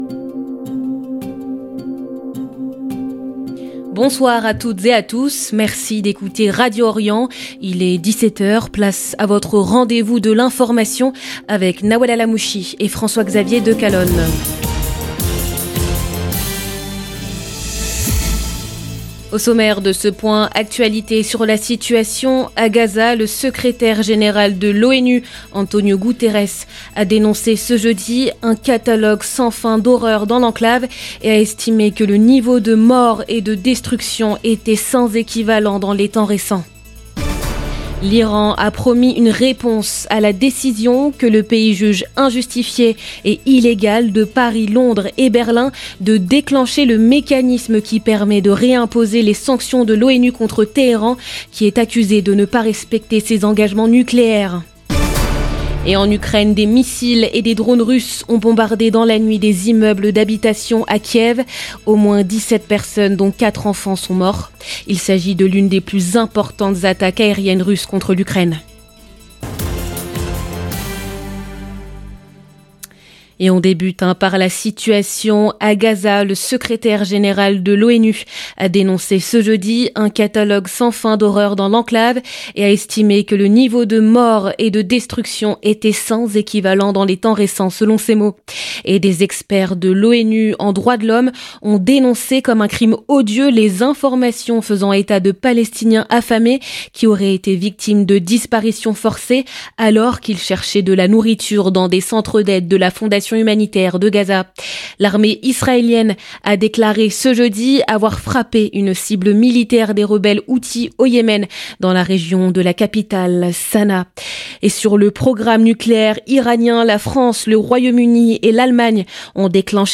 Magazine d'information de 17H du 28 août 2025